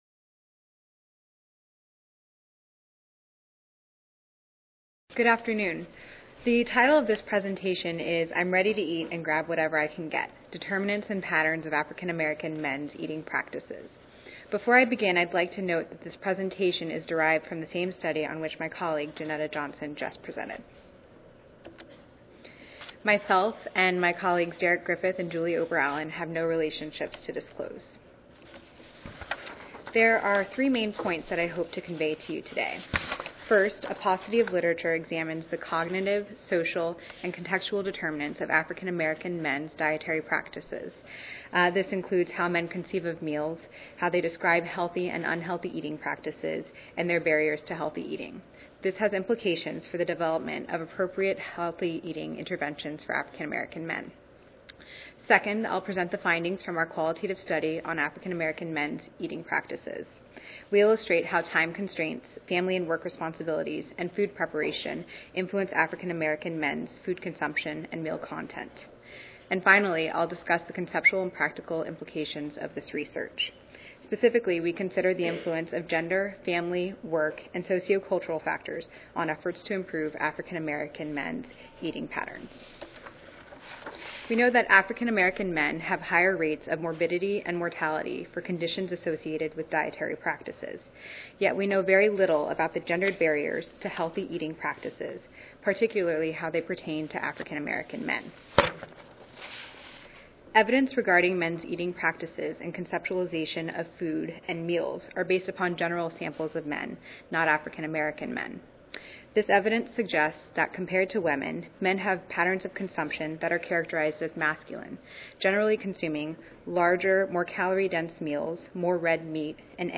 This presentation describes how African American men conceptualize healthy and unhealthy eating, define different meals and consider the factors that influence their eating patterns. We conducted a thematic analysis of nine exploratory focus groups with 83 middle-aged, urban African American men in southeast Michigan.